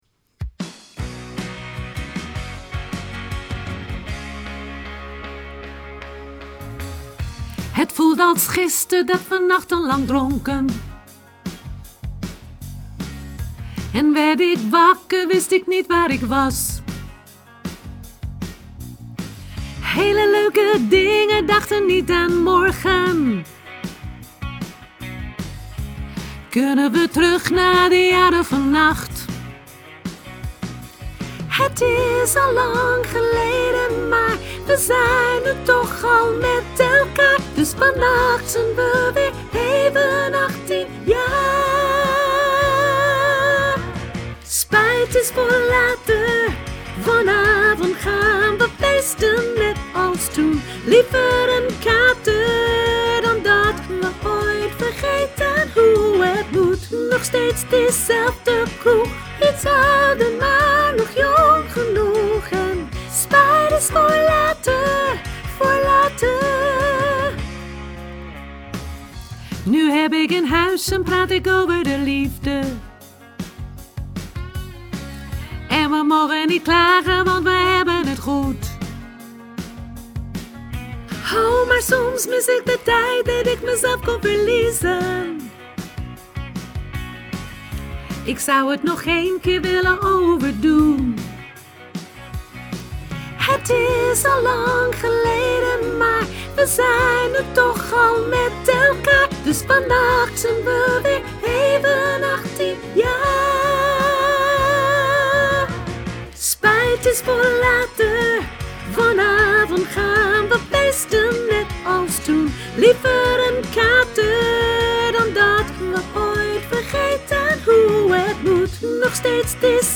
sopraan hoog